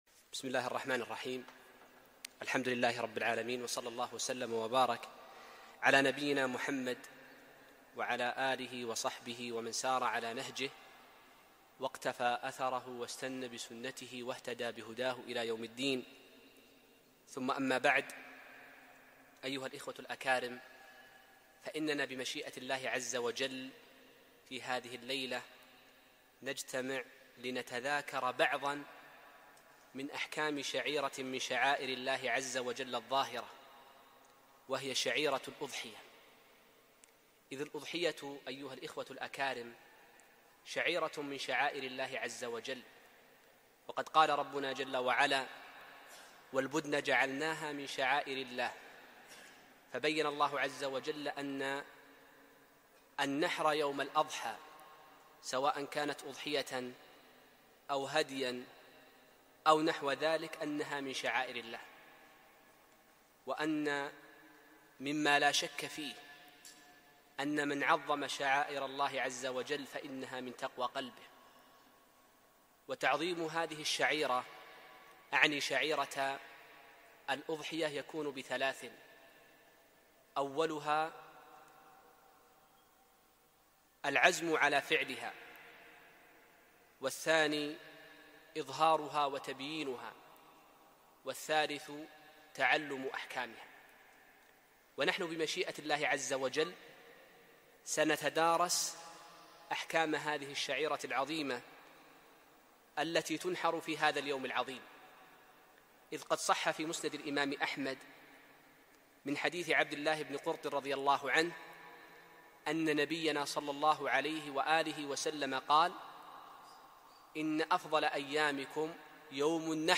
محاضرة - أحكام الأضحية 1429